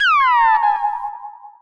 cartoon_funny_warp_01.wav